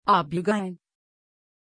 Pronunția numelui Abygael
pronunciation-abygael-tr.mp3